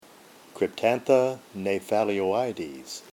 Pronunciation/Pronunciación:
Cryp-tán-tha gna-pha-li-o-í-des